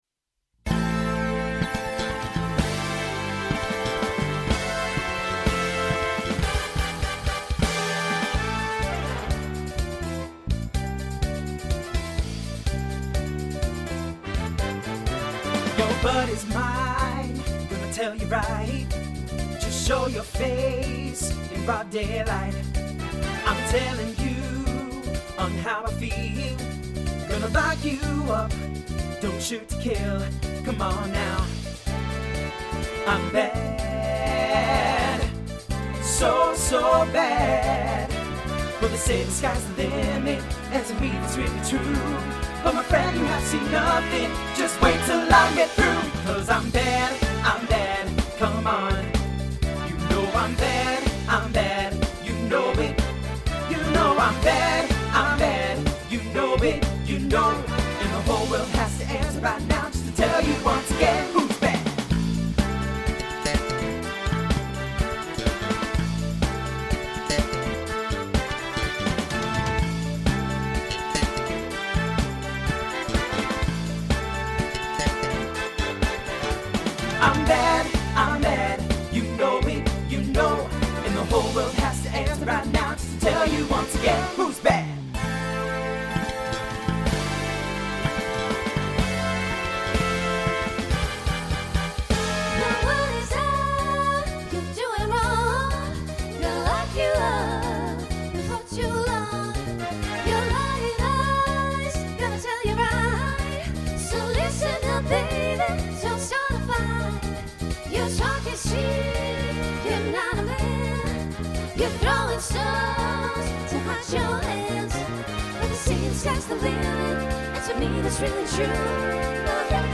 TTB/SSA/SATB
Voicing Mixed
Genre Pop/Dance